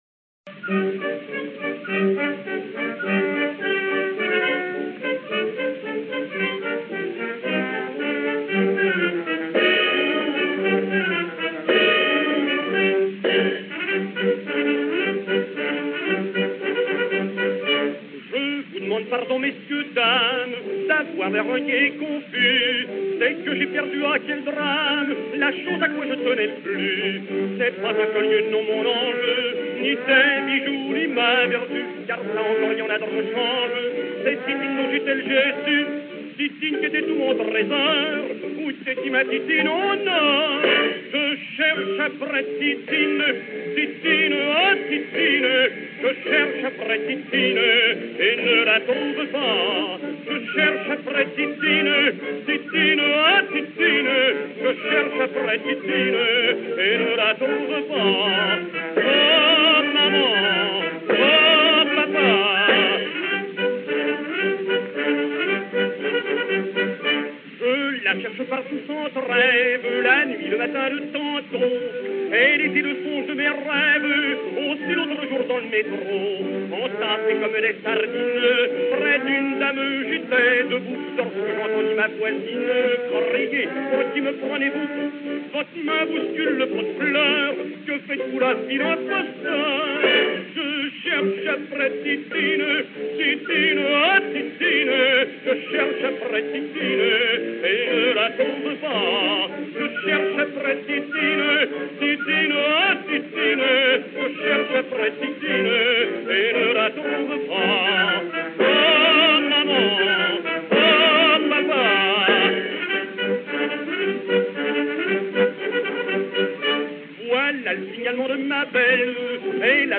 chanson humoristique